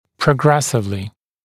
[prə’gresɪvlɪ][прэ’грэсивли]постепенно (с восходящей или нисходящей динамикой)